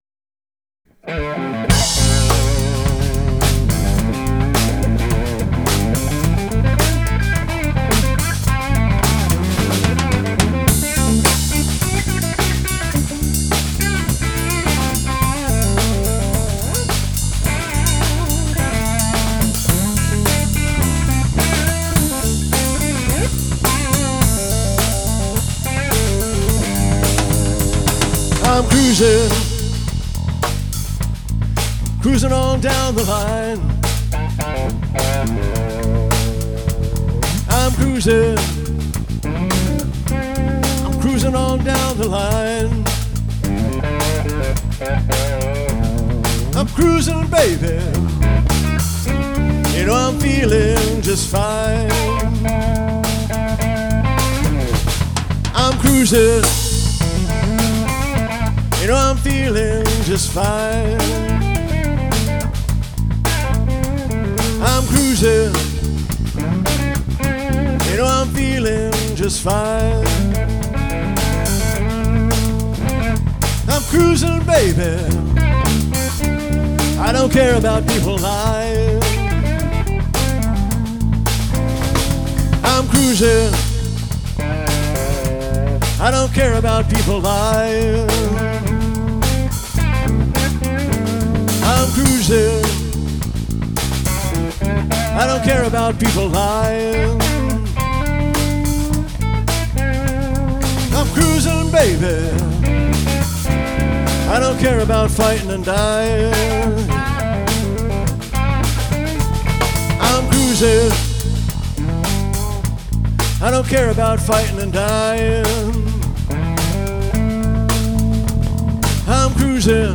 Guitar
Drums
Bass and vocals